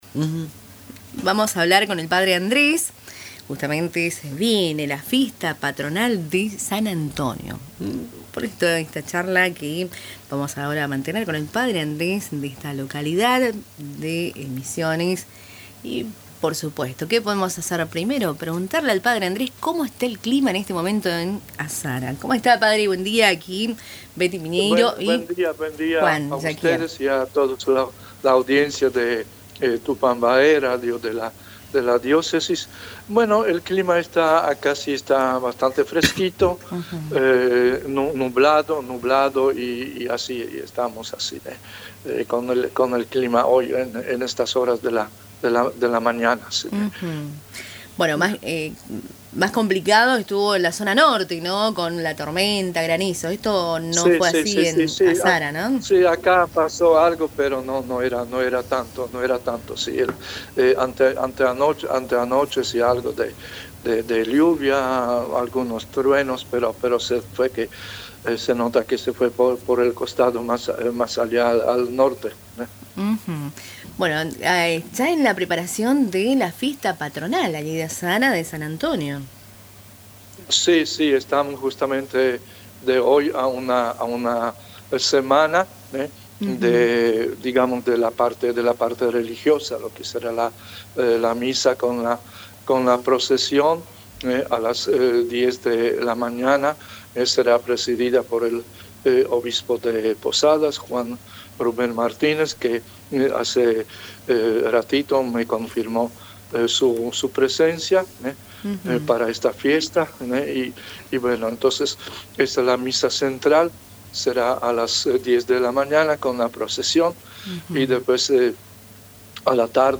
En diálogo con Radio Tupambaé